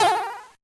jump2.ogg